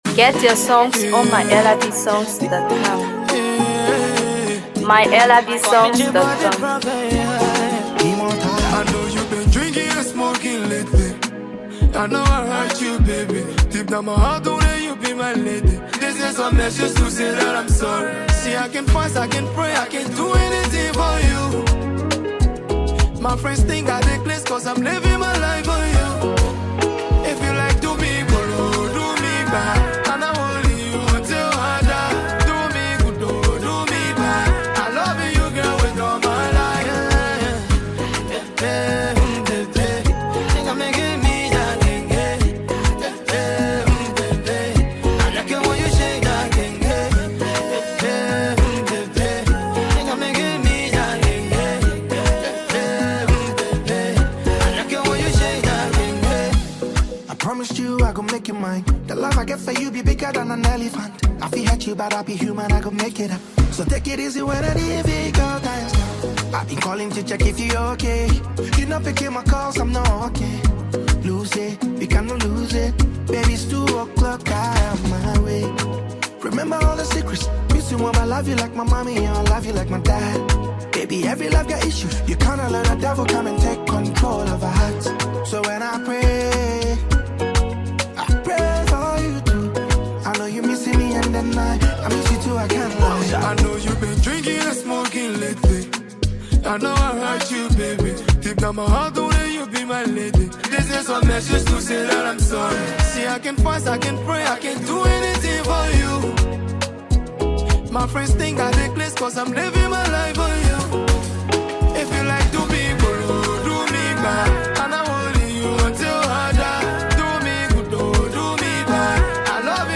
captivating and soulful musical piece
infused with traditional Liberian sound
Ghanaian Afrobeat flavor